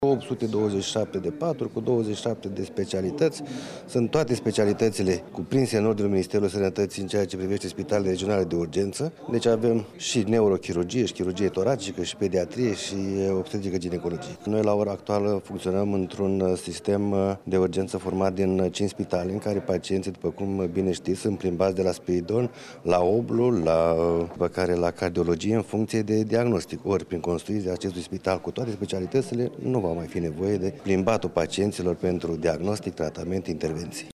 Directorul Direcţiei de Sănătate Publică Iaşi, Liviu Stafie,  a precizat că în prezent un bolnav este supus investigaţiilor medicale la cele cinci spitale de profil din municipiul Iaşi şi odată cu terminarea lucrărilor la viitorul spital regional acest lucru va putea fi evitat: